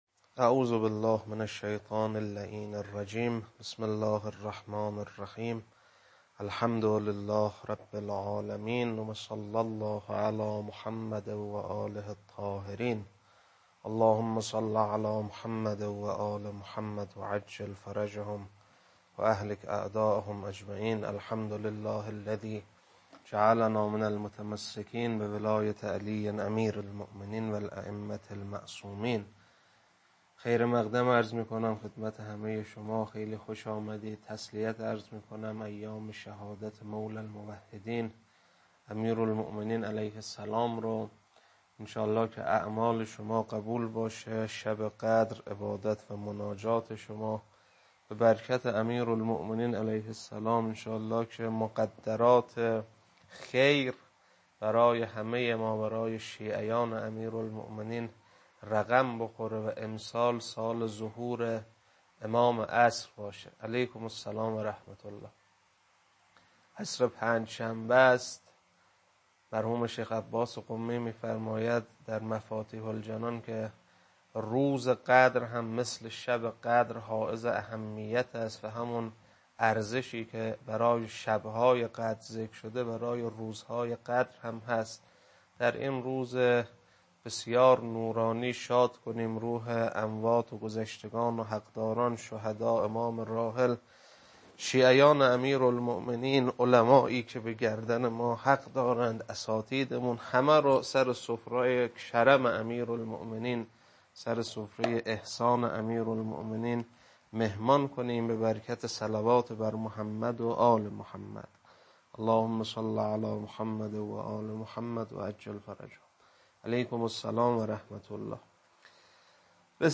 خطبه 7.mp3
خطبه-7.mp3